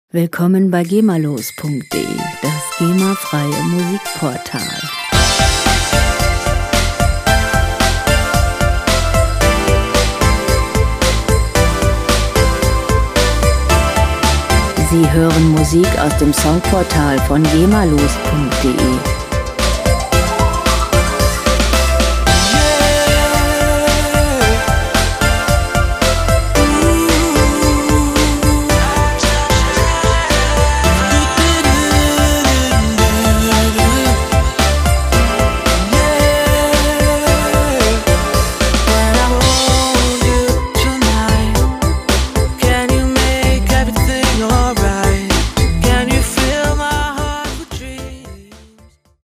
Gemafreie moderne Weihnachtslieder
Musikstil: Pop
Tempo: 112 bpm
Tonart: C-Dur
Charakter: seicht, modern
Instrumentierung: Bells, Synthie, Gesang, Gitarrensolo